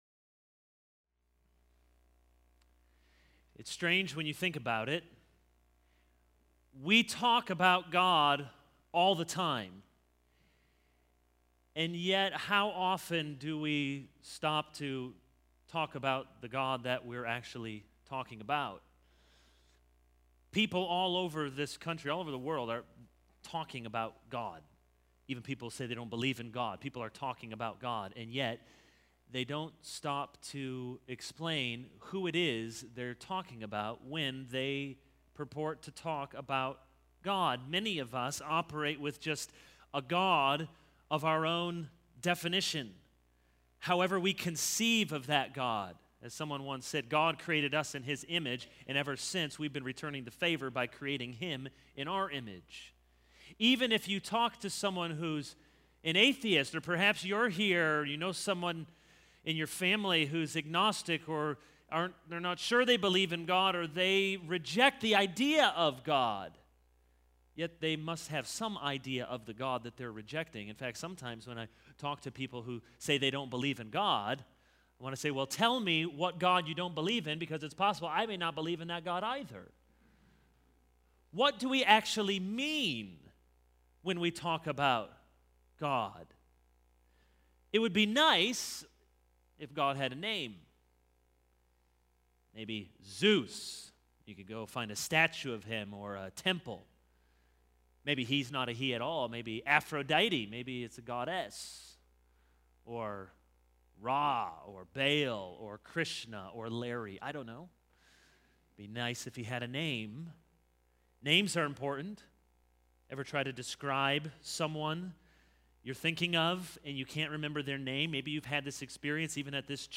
This is a sermon on Exodus 3:13-15.